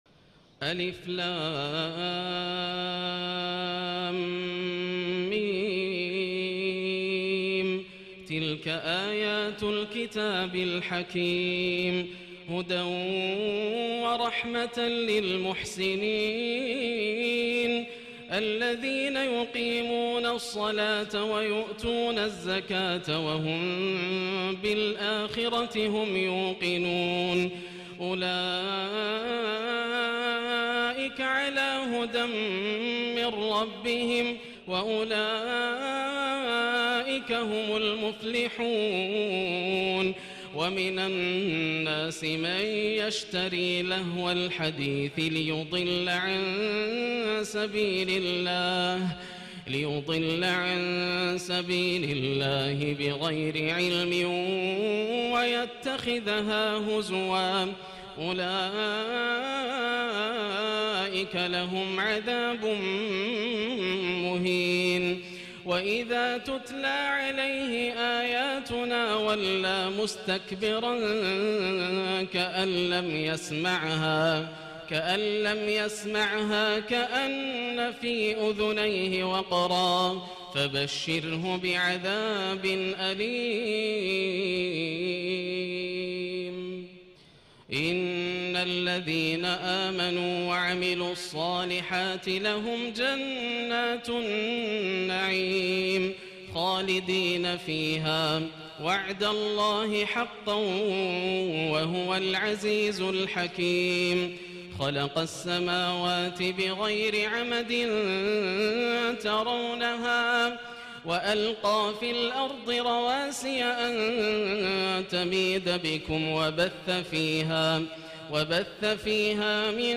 الليلة الأخيرة من تراويح رمضان 1437هـ > الليالي الكاملة > رمضان 1437هـ > التراويح - تلاوات ياسر الدوسري